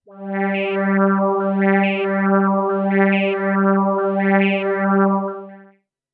标签： FSharp4 MIDI音符-67 KORG-Z1 合成 单注 多重采样
声道立体声